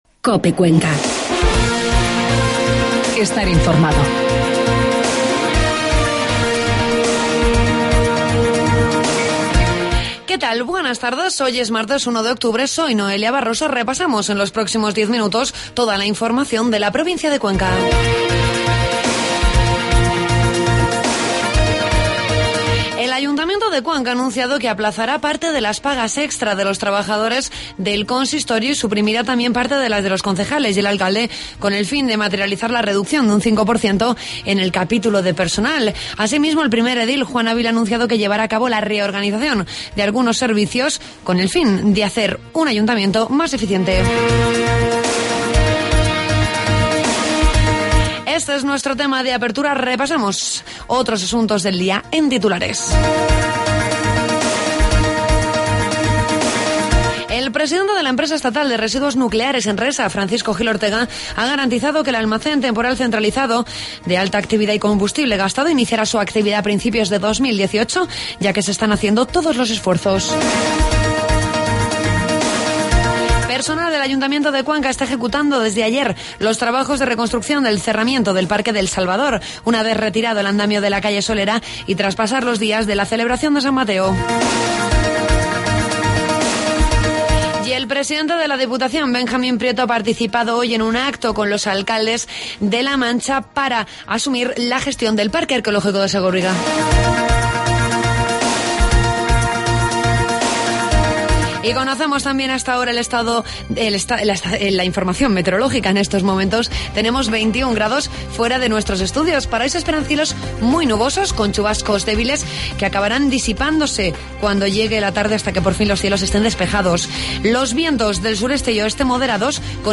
AUDIO: Toda la información de la provincia de Cuenca en los informativos de mediodía de COPE